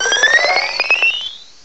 Add all new cries
cry_not_vivillon.aif